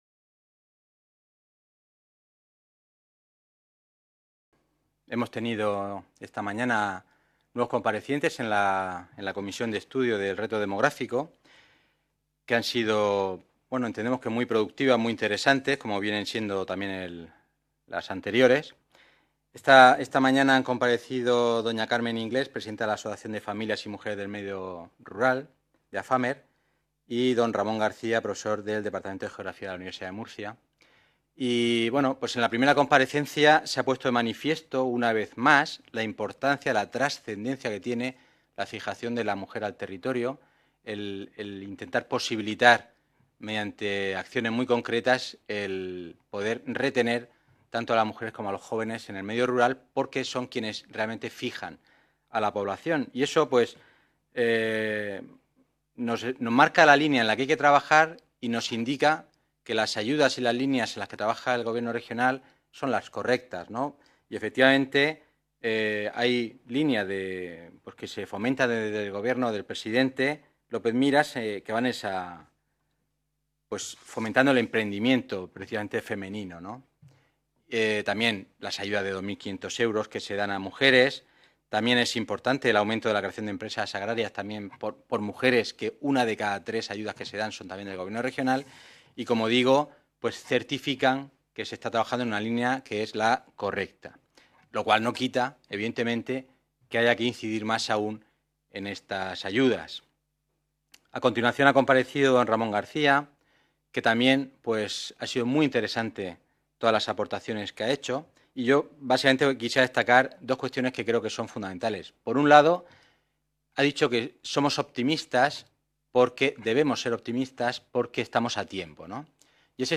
Ruedas de prensa tras la Comisión Especial de Estudio para abordar el Reto Demográfico y la Despoblación en la Región de Murcia
• Grupo Parlamentario Popular